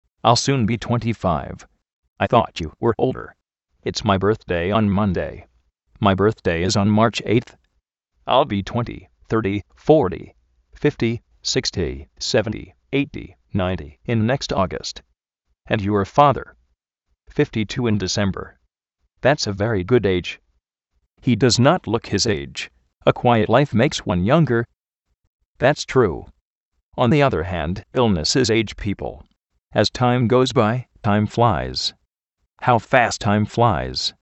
jápi bérzdei
méni jápi ritérns ov de déi
jáu óuld áriu?
áim tuénti-fáiv (íars óuld)
iáng, óuld